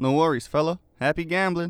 Voice Lines
Update Voice Overs for Amplification & Normalisation